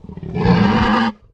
boar_panic_1.ogg